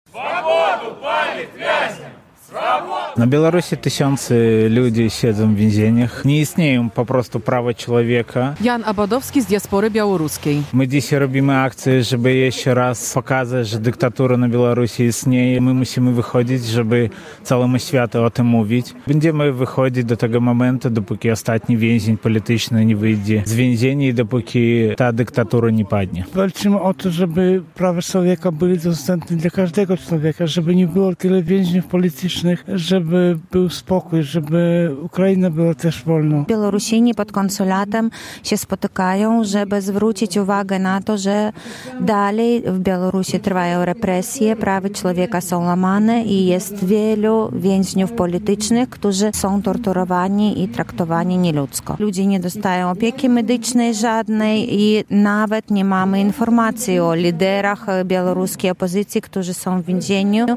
relacja
W czasie manifestacji przed konsulatem Białorusi w Białymstoku, przedstawiciele diaspory przywoływali dane dotyczące represji reżimu Aleksandra Łukaszenki.